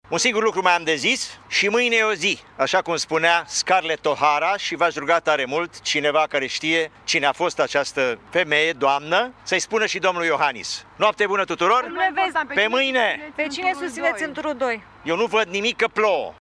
‘Le mulţumesc celor care m-au votat, celor care au preferat prin vot un preşedinte nepartizan, un preşedinte independent, un preşedinte al tuturor românilor. Un singur lucru am de zis: şi mâine e o zi, cum spunea Scarlett O’Hara, şi, dacă ştie cineva cine este această doamnă, această femeie, să-i spună şi domnului Iohannis‘, a afirmat Diaconu în faţa Ateneului Român.